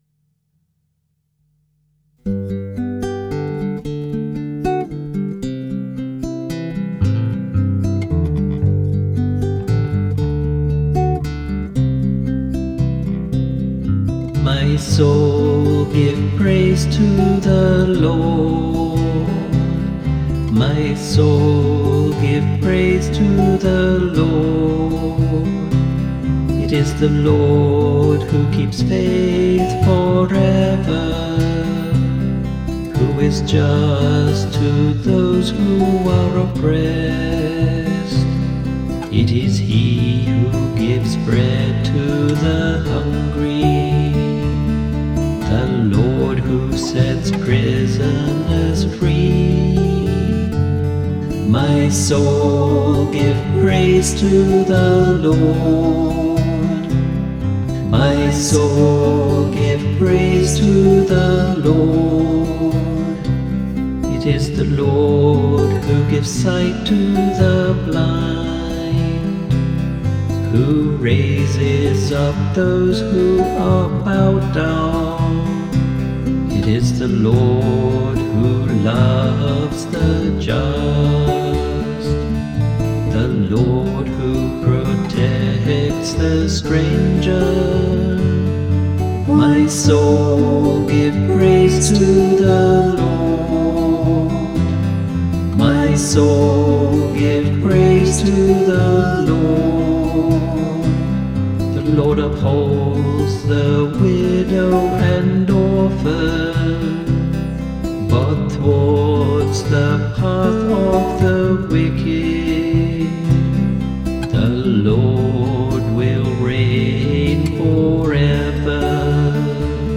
Responsorial Psalm 145(146):7-10
Music by the Choir of Our Lady of the Rosary RC Church, Verdun, St. John, Barbados.